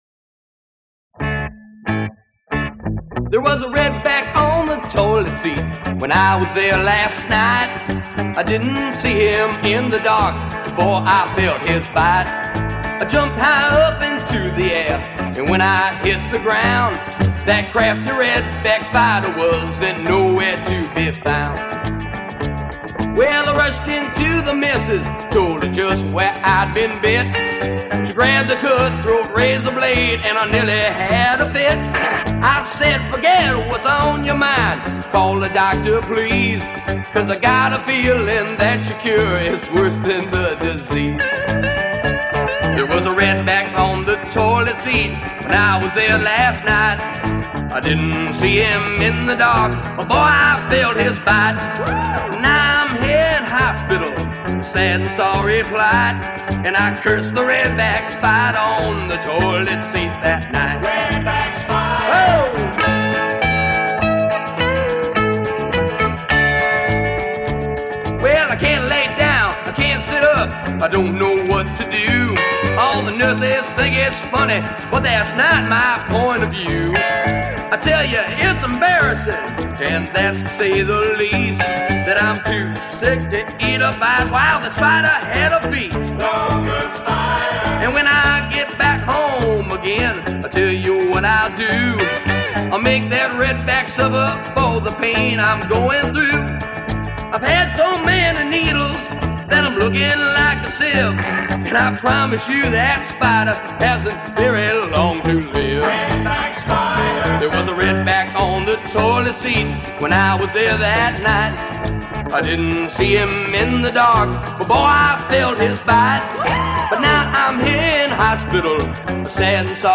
Speaking of red backs, there is a beer (of course) named after it as well as this humorous folk song that every good Aussie knows: